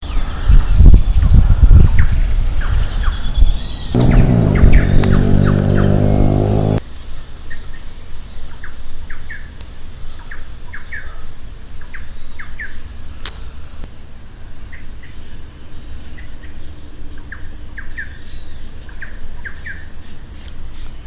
Thrush-like Wren (Campylorhynchus turdinus)
Life Stage: Adult
Location or protected area: Ituzaingó
Condition: Wild
Certainty: Recorded vocal
Ratona-grande.mp3